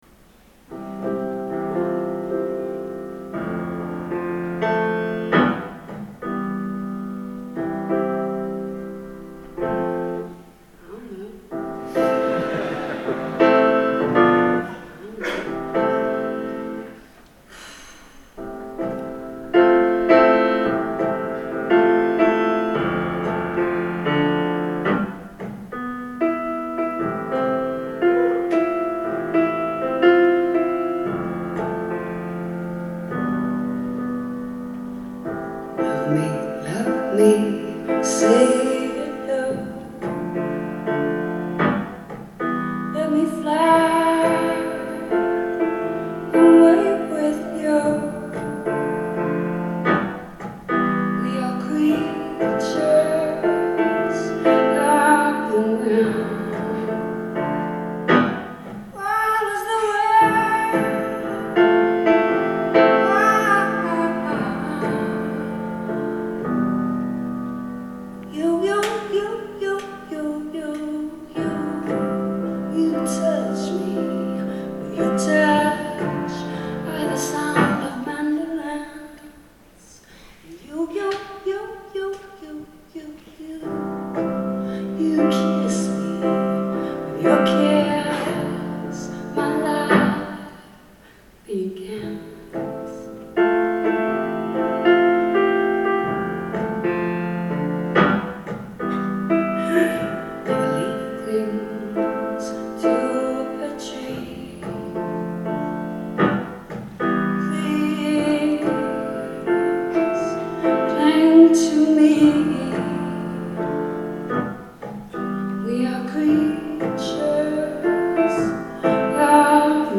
Live at the Berklee Performance Center
Boston, MA